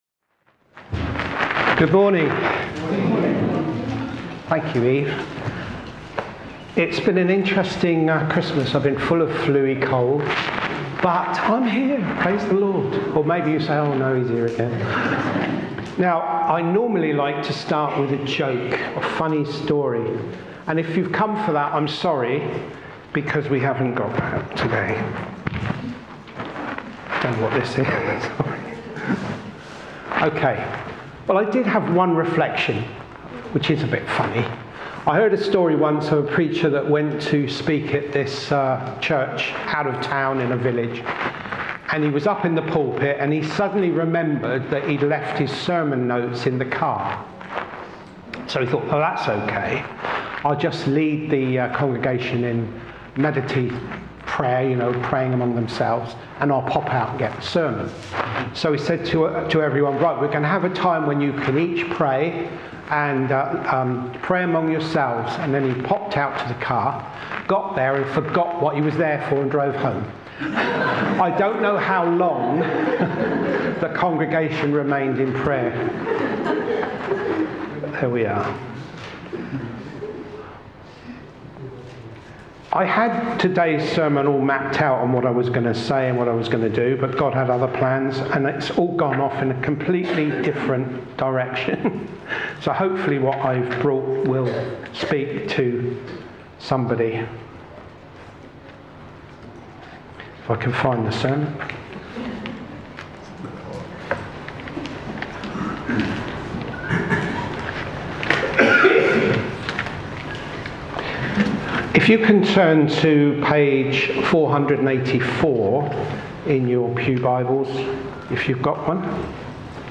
Talks and Sermons - Thornhill Baptist Church